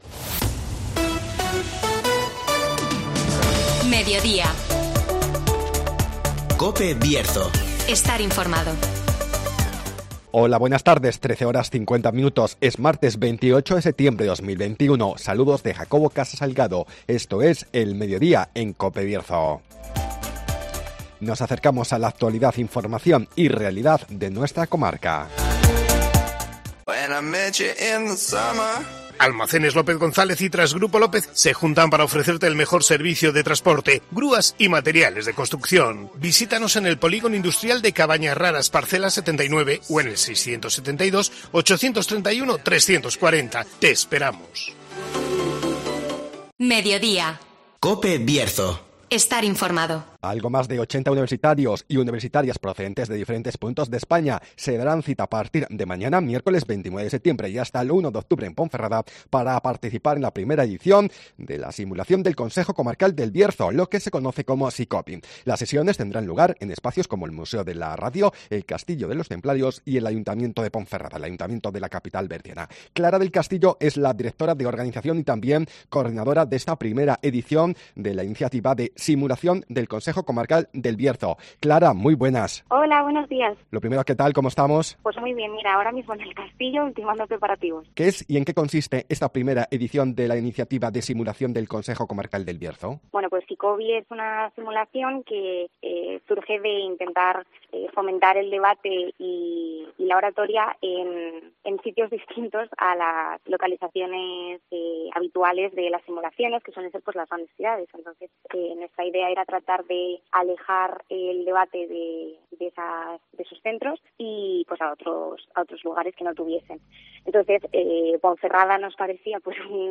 Estudiantes procedentes de toda España participan en una simulación parlamentaria del Consejo Comarcal (Entrevista